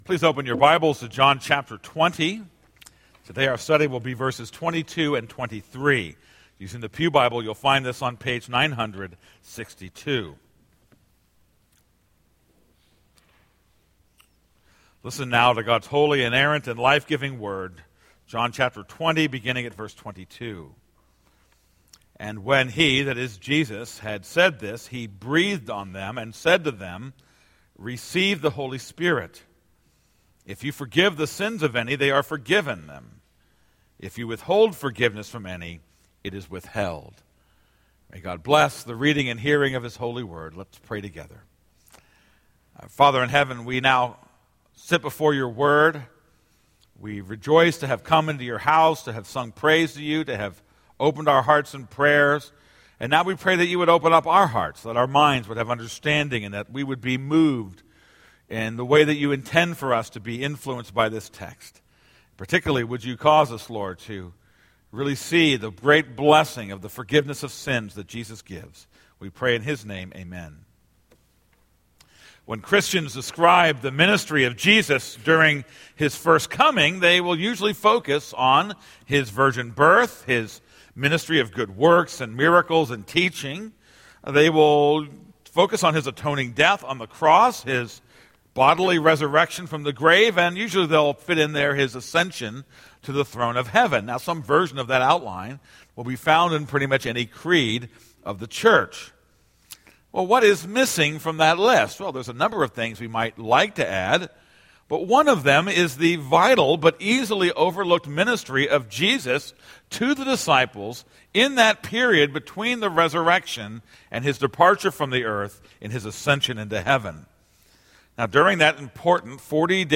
This is a sermon on John 2:22-23.